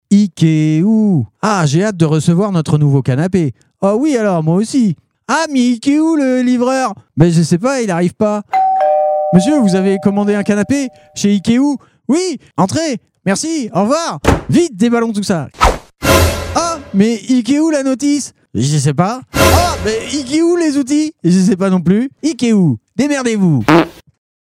Fausses Pubs RADAR